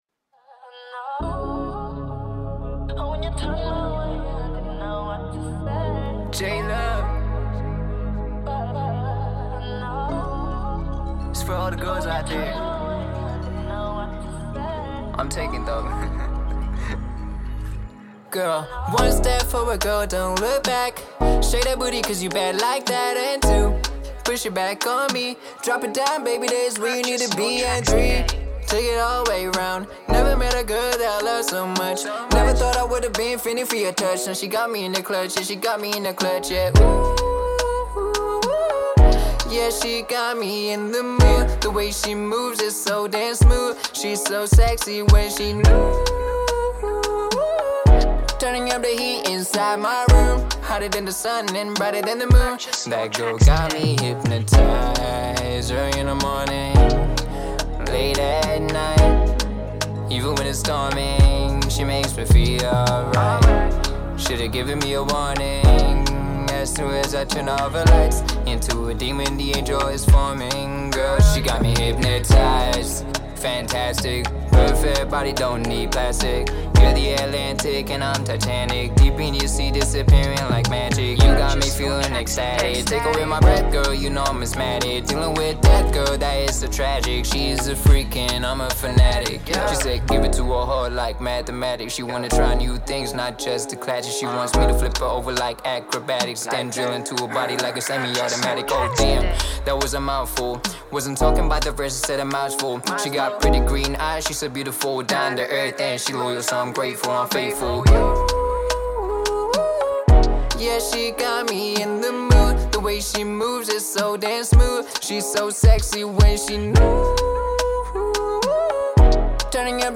Song Recording
Singer/Songwriter